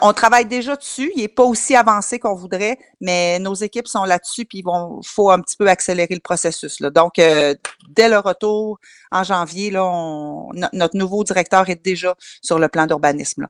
En entrevue, elle a mentionné l’importance de bien mettre à jour ce plan.